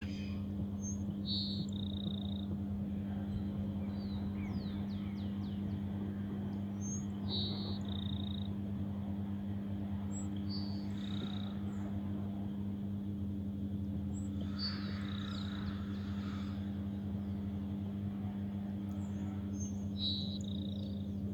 Tico-tico-do-campo (Ammodramus humeralis)
Nome em Inglês: Grassland Sparrow
País: Argentina
Condição: Selvagem
Certeza: Observado, Gravado Vocal
Cachilo-ceja-amarilla-1_1_1_1_1.mp3